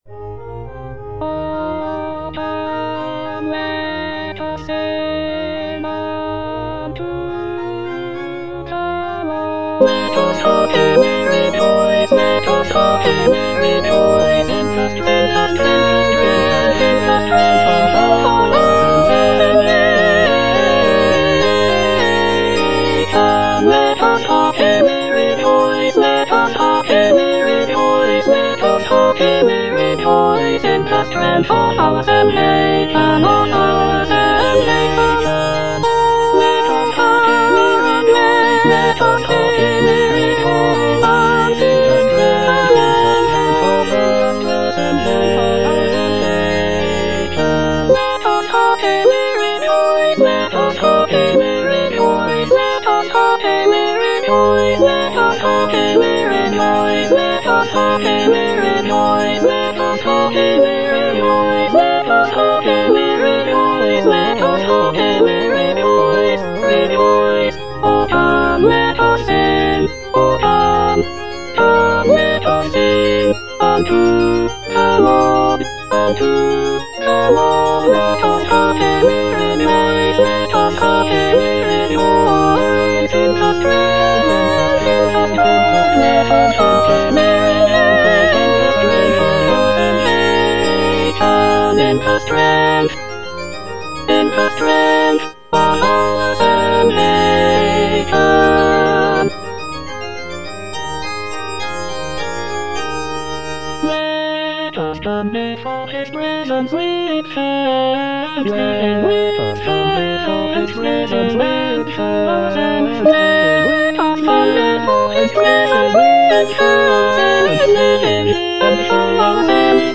Choralplayer playing O come, let us sing unto the Lord - Chandos anthem no. 8 HWV253 (A = 415 Hz) by G.F. Händel based on the edition CPDL #09622
The use of a lower tuning of A=415 Hz gives the music a warmer and more resonant sound compared to the standard tuning of A=440 Hz.
G.F. HÄNDEL - O COME, LET US SING UNTO THE LORD - CHANDOS ANTHEM NO.8 HWV253 (A = 415 Hz) O come, let us sing unto the Lord - Soprano (Emphasised voice and other voices) Ads stop: auto-stop